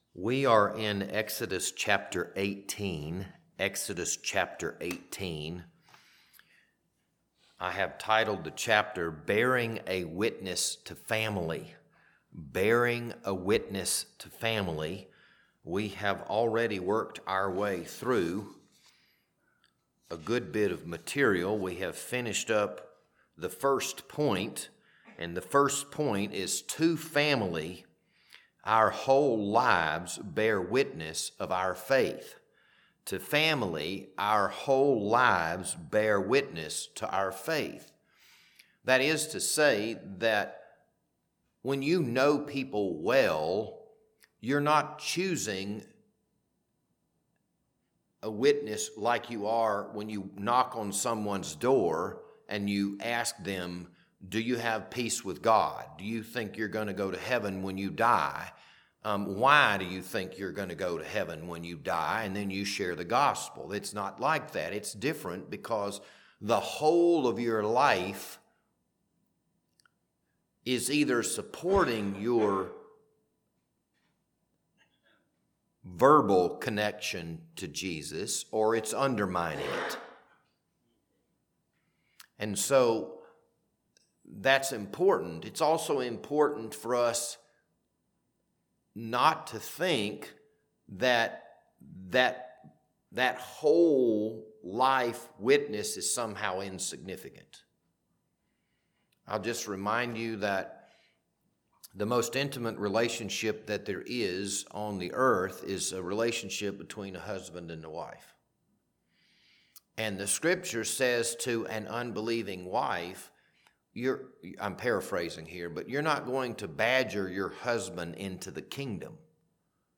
This Wednesday evening Bible study was recorded on April 22nd, 2026.